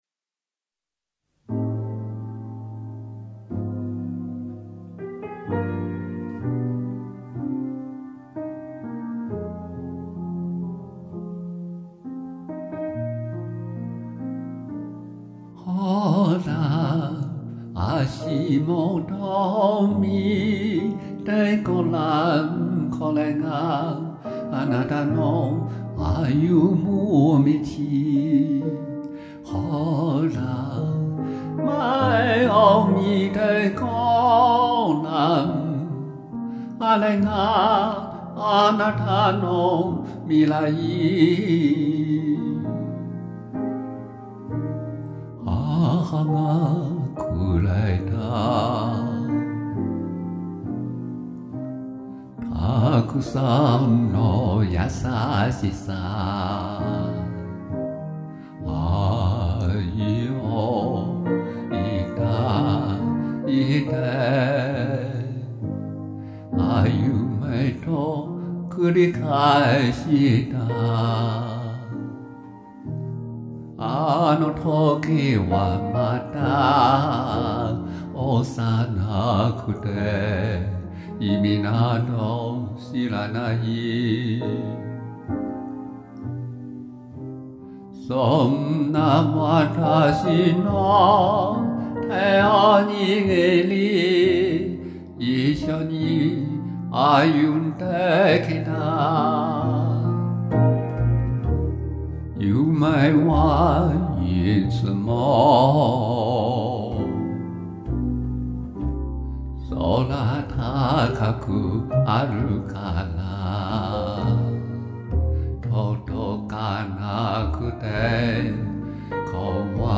日語感覺很正宗啊，好聽。有意境。